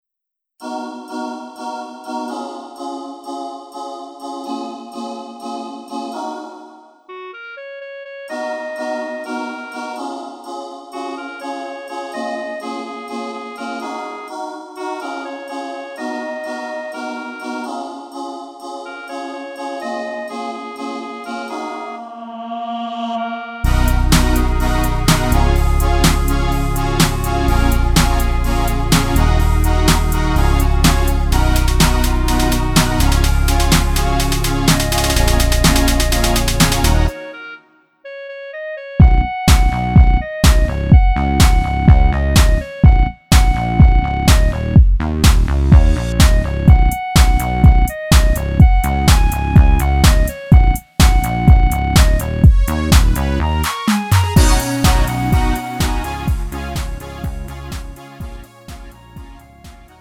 음정 -1키 2:39
장르 가요 구분